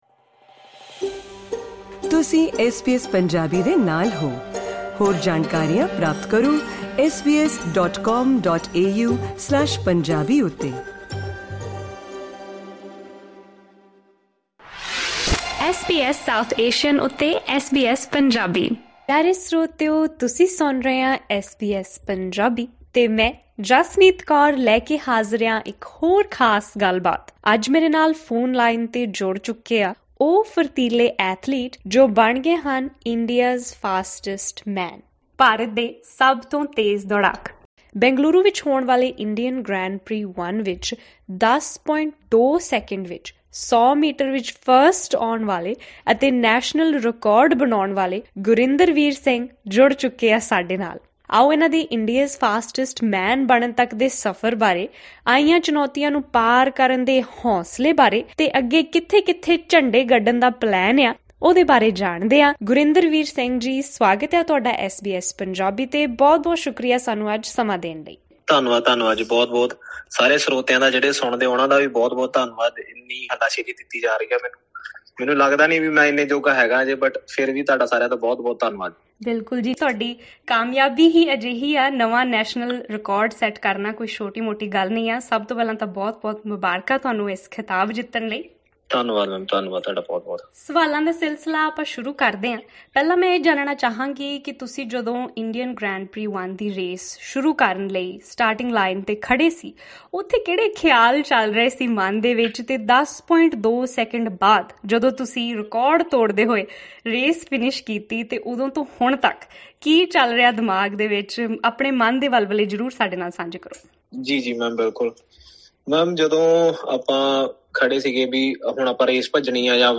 Exclusive Interview: Gurindervir Singh on becoming ‘India’s Fastest Man’ with a 10.2s 100m sprint